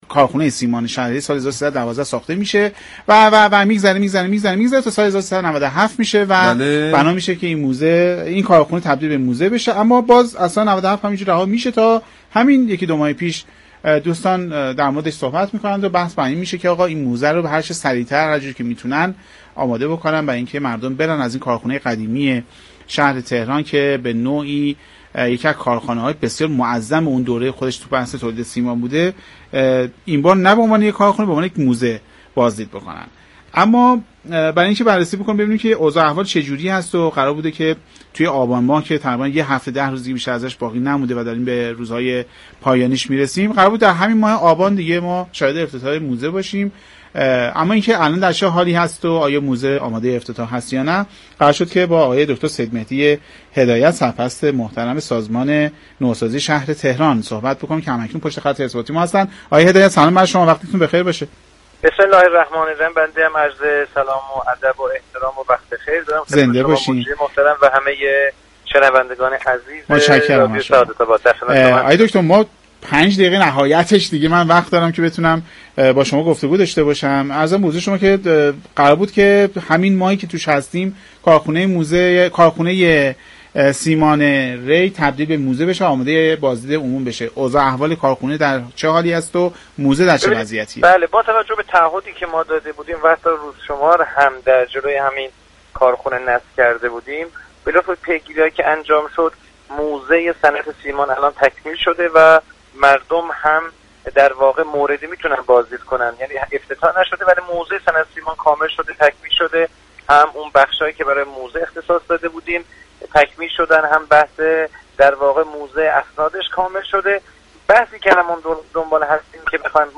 برنامه سعادت آباد 18 آبان این موضوع را از سیدمهدی هدایت سرپرست سازمان نوسازی شهر تهران جویا شد.